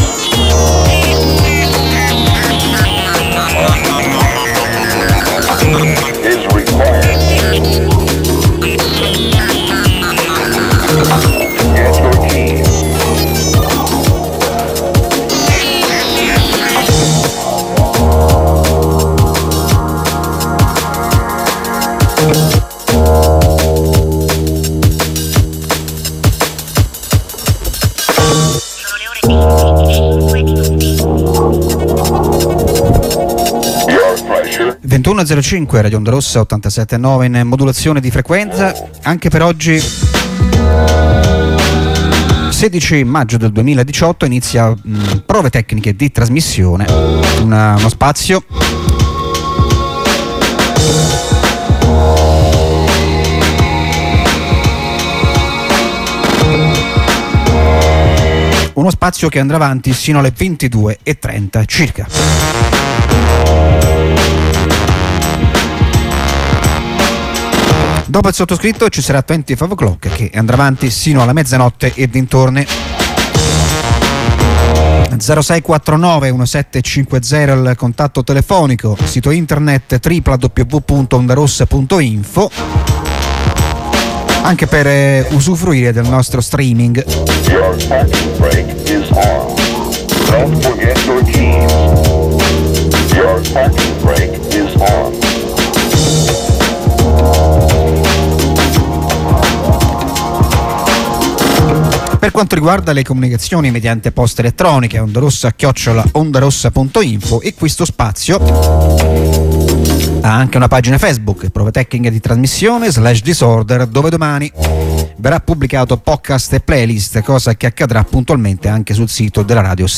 Industrial;Ambient;Noise(10577) | Radio Onda Rossa